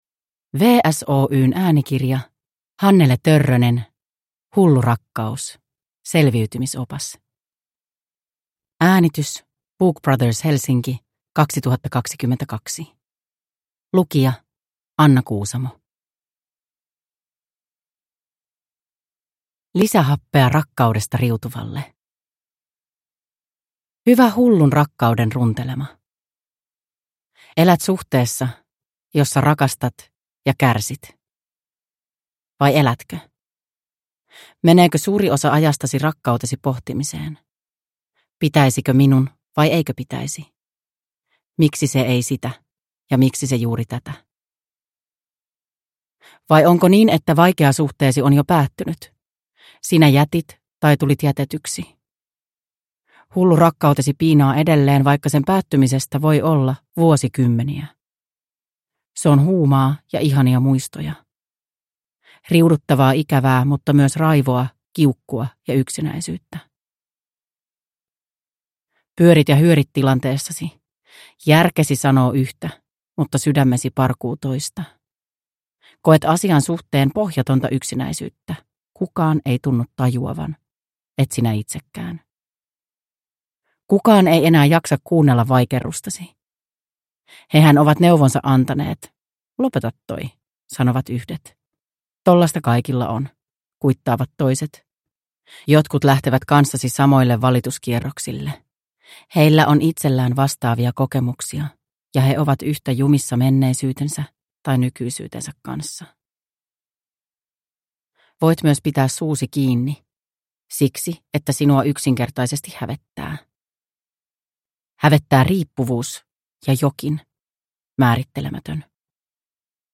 Hullu rakkaus. Selviytymisopas – Ljudbok – Laddas ner